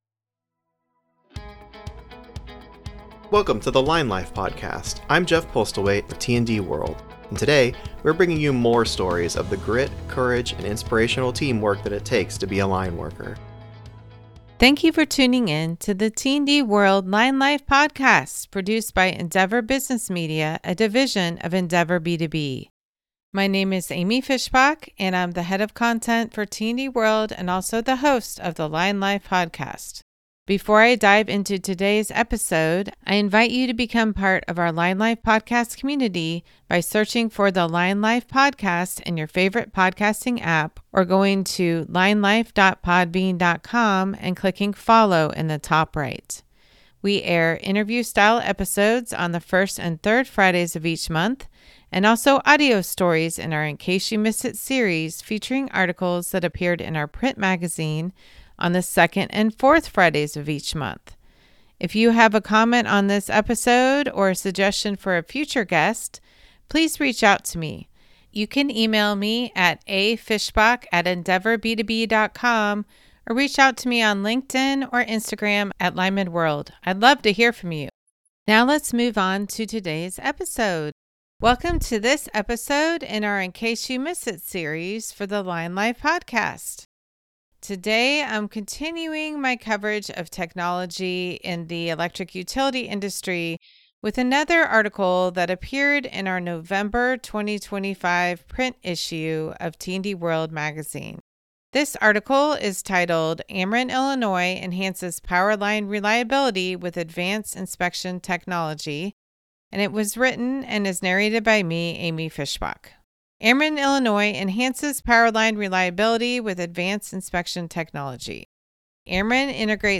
In this ICYMI episode of the Line Life Podcast, we are sharing the narrated version of an article from the November 2025 issue of T&D World magazine on how Ameren Illinois is using new technology to inspect aging sub-transmission conductors and prioritize line rebuilds.